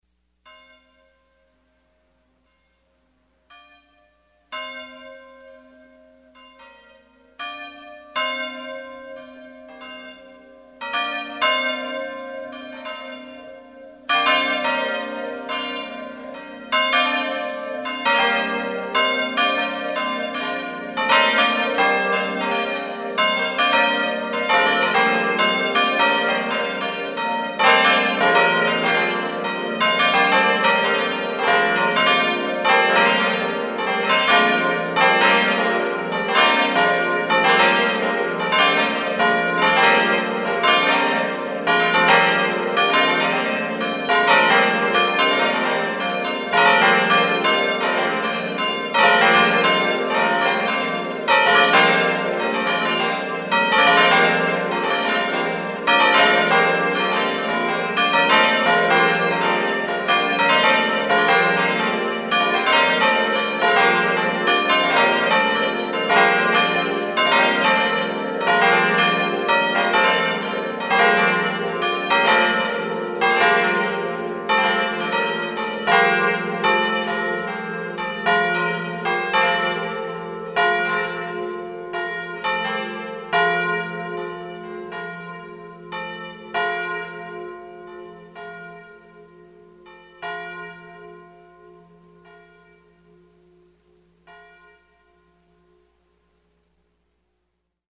The Supreme carillon offers the true sound of cast bronze bells in a digital carillon with a flexible operating system.
Six bell peal – Cast Bronze Bells
4939-six-bell-peal-Verdin Bells.mp3